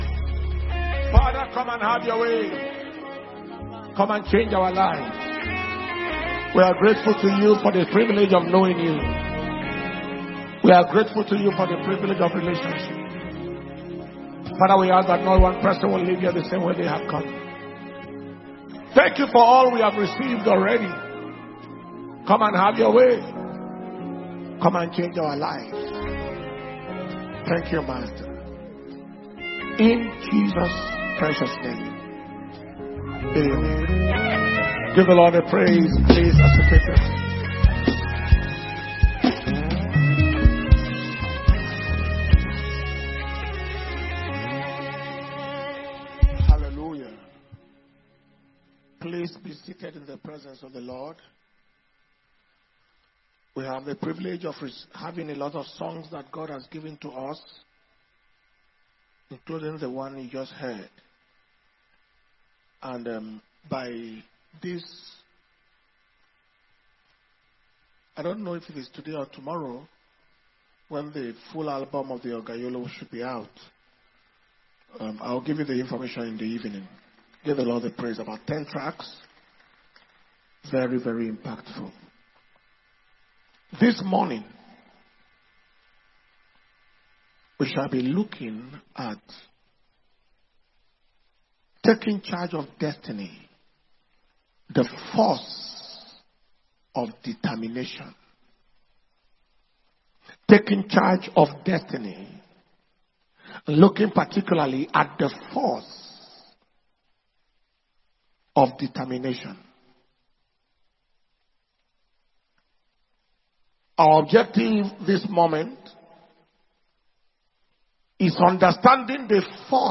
Destiny Recovery Convention May 2025